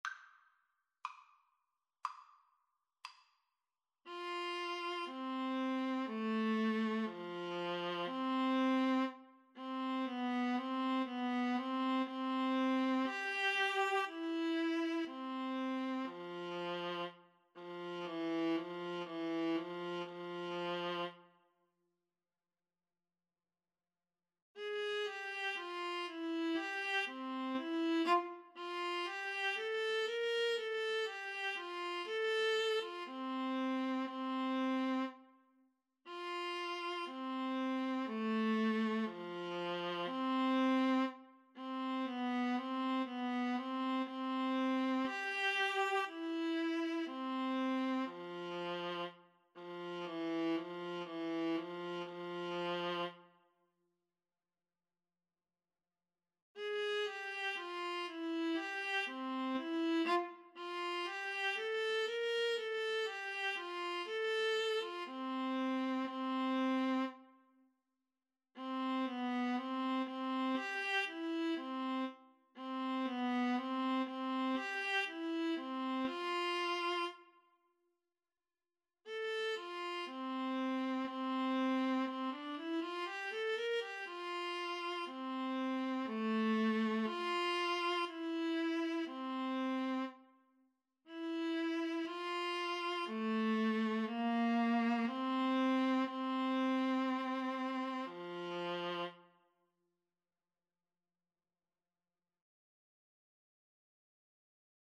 Classical (View more Classical Clarinet-Viola Duet Music)